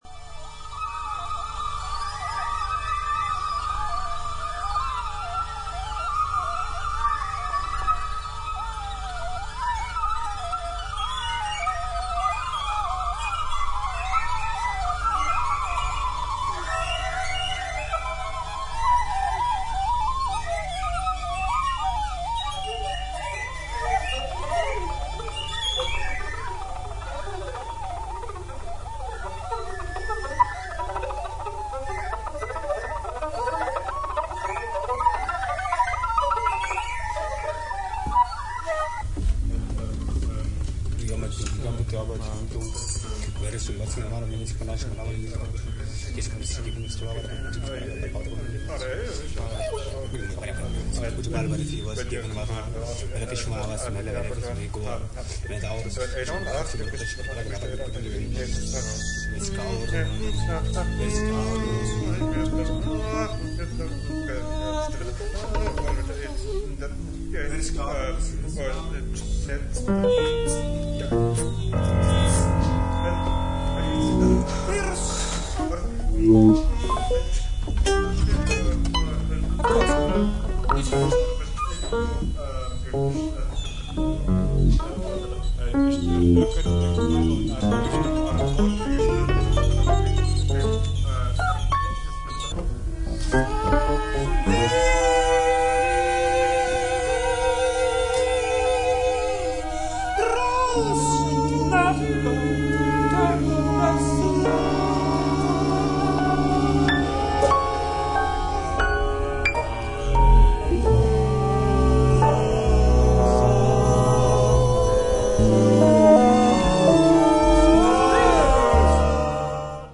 生き物の声のようなギターの音色にサウンドコラージュ的手法も取り入れられた
ジャズやプログレッシブロックの要素に加え、スコア中にインプロヴィゼーションも織り交ざるイギリスの前衛ロック名盤